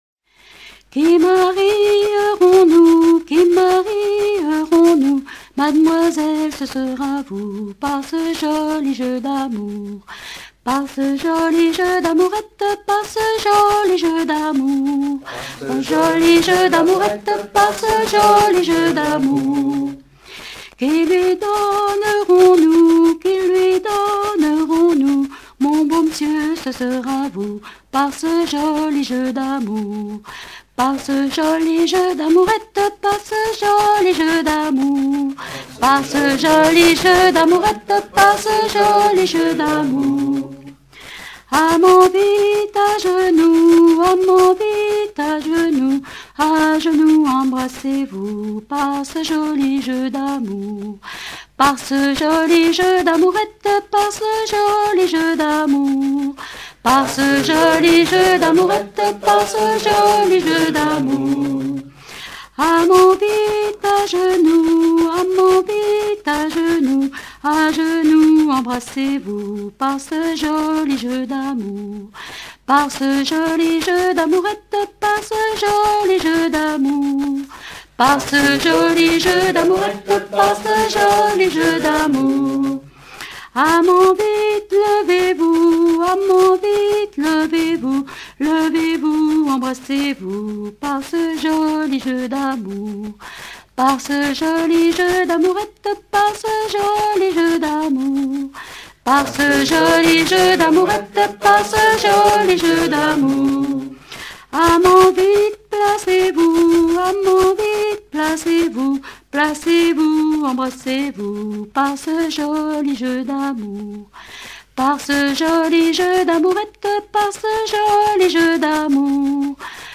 Rondes enfantines à baisers ou mariages
danse : ronde à marier